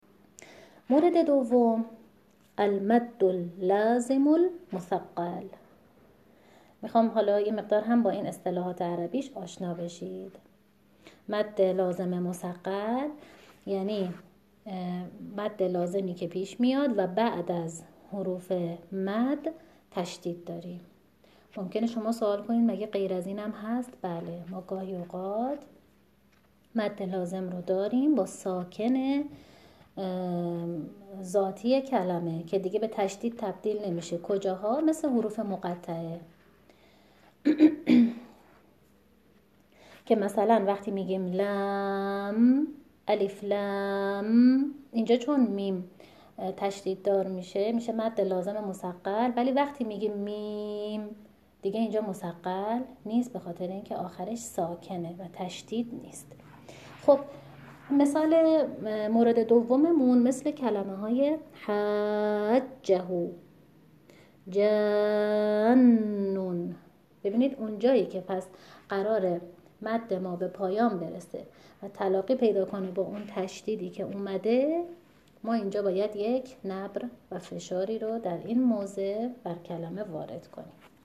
همانطور که می‌شنوید این دو مورد ربطی به وزن کلمه ندارد، چون آهنگ ذاتی کلمه است، اما باید برای این مواضع، فشار وارد کنیم.